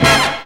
JAZZ STAB 30.wav